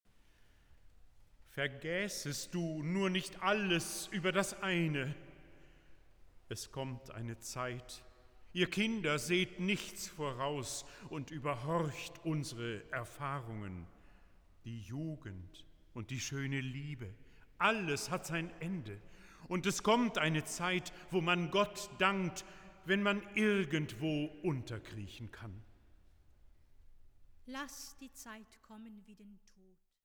Oboe
Trompete